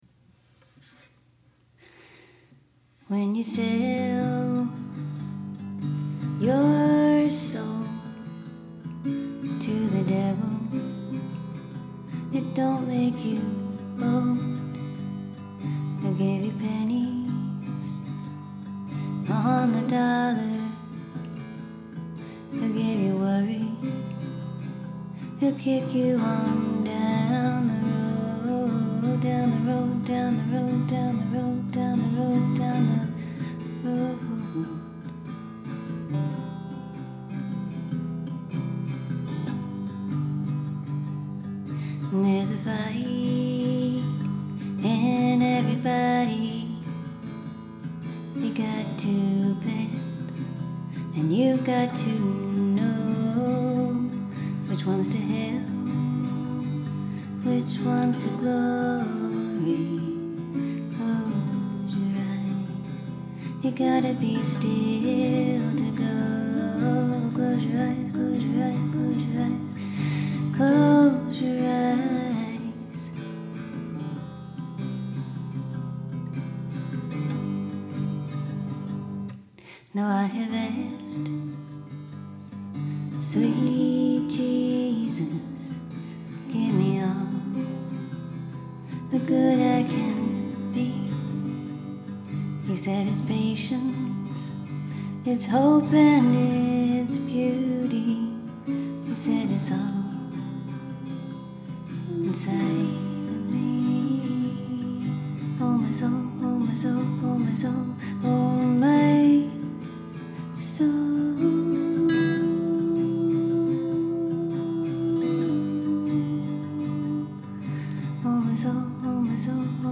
iphone recording, written July 2024